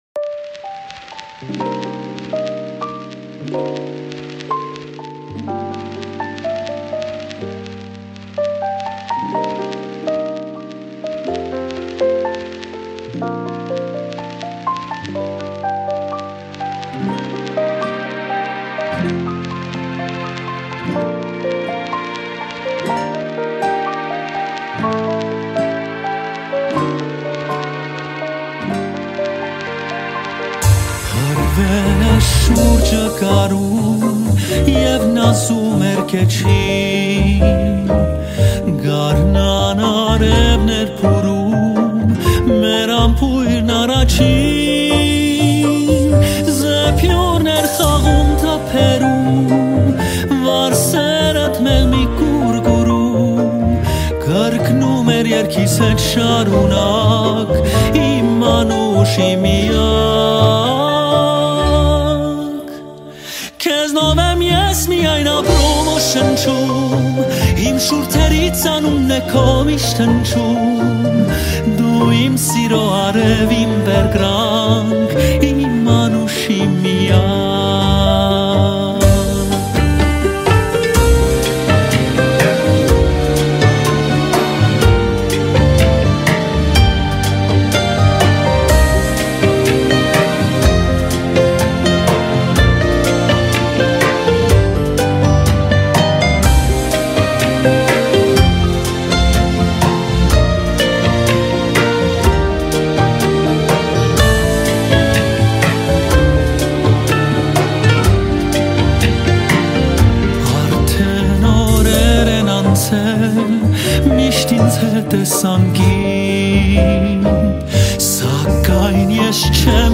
Erger 2017, Армянская музыка